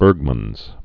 (bûrgmənz)